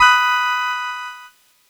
Cheese Chord 17-B3.wav